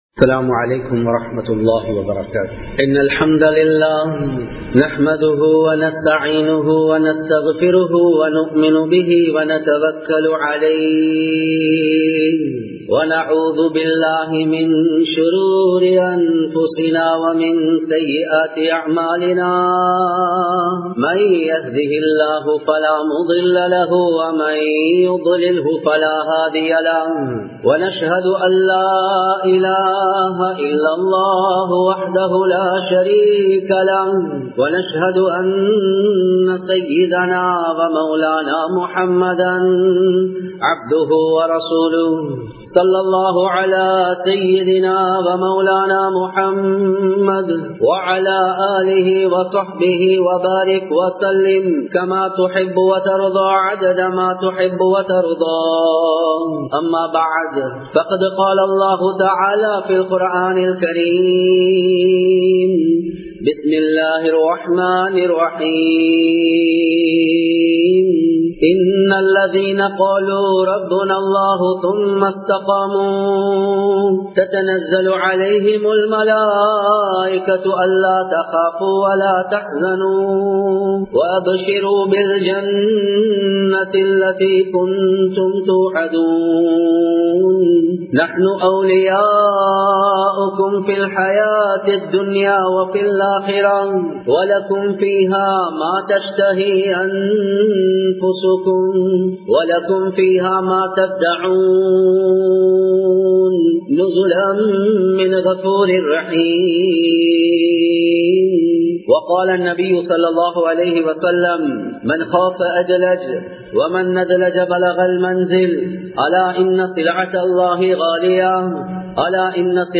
உளத்தூய்மை | Audio Bayans | All Ceylon Muslim Youth Community | Addalaichenai
Colombo 07, Jawatha Jumua Masjith